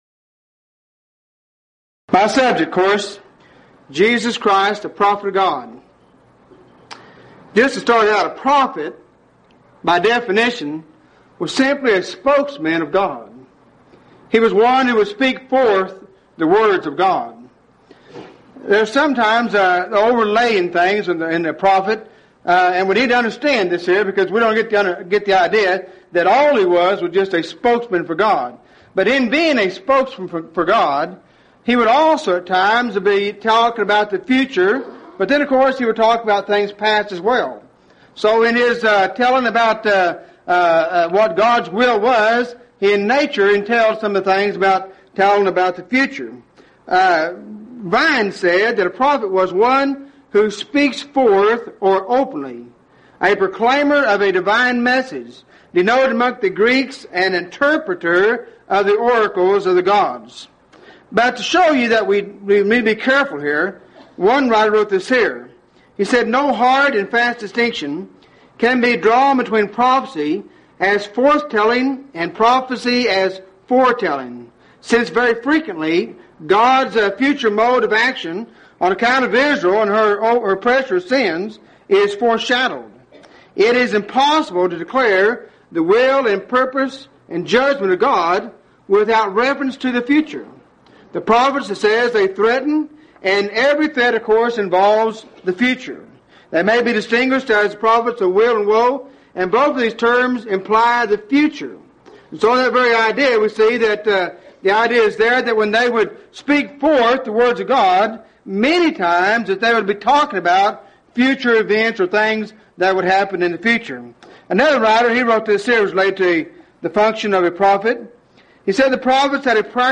Series: Lubbock Lectures Event: 2nd Annual Lubbock Lectures